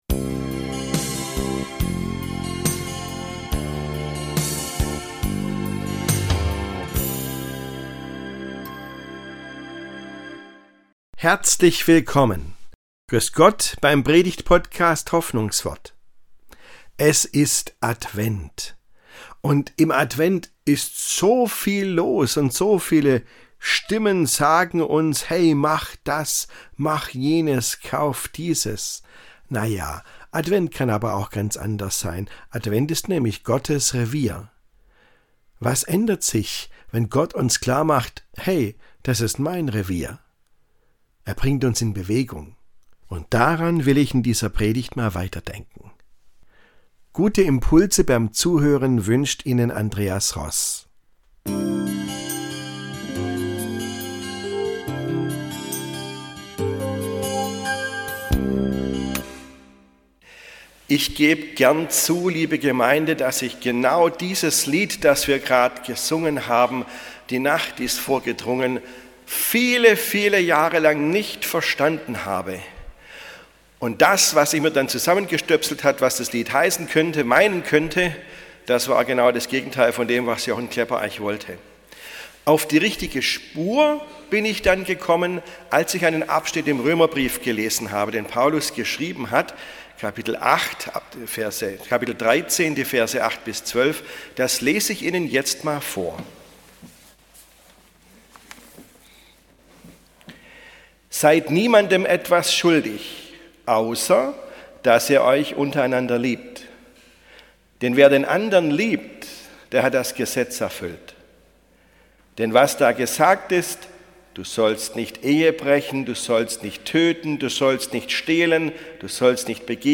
Advent: Gottes Revier ~ Hoffnungswort - Predigten